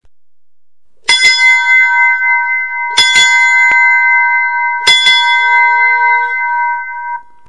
Звуки кораблей
Звон корабельной склянки